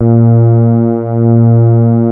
P.5 A#3.4.wav